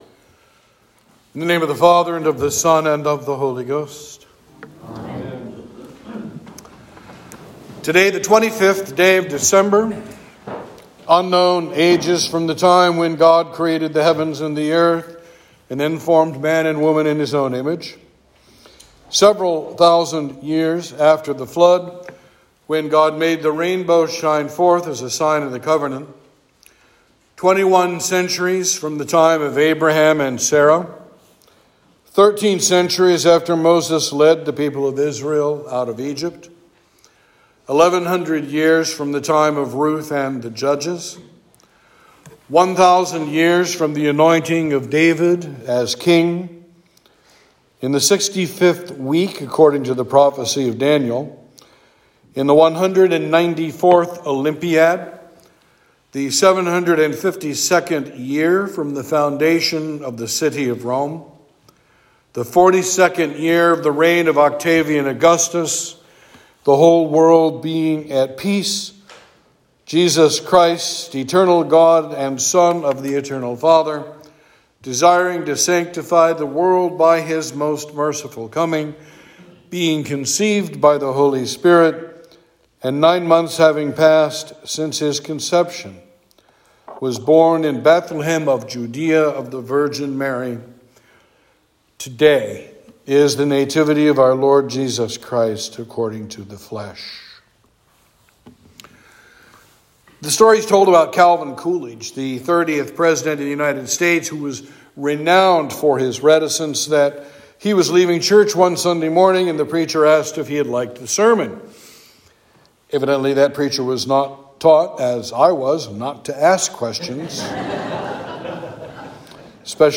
Sermon for Christmas Day